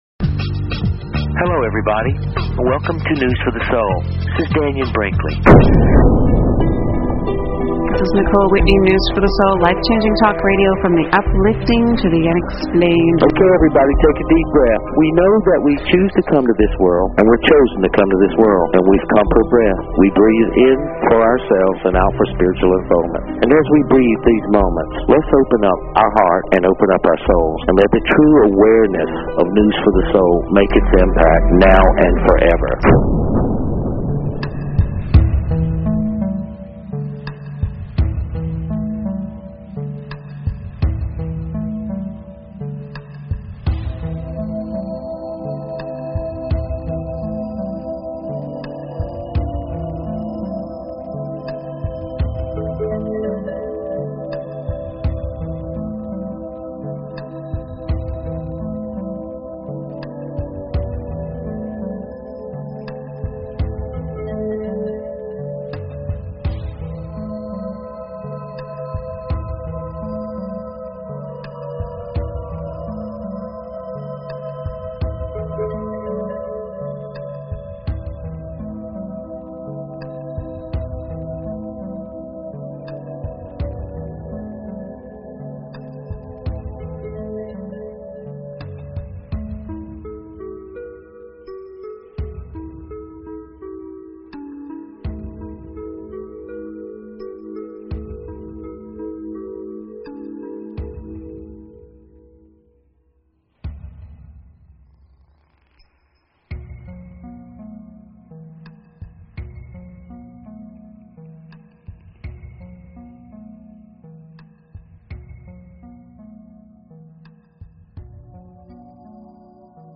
Courtesy of BBS Radio
Whether it's live energy healings on the air or an on-location paranormal investigation, it's all uplifting in the end.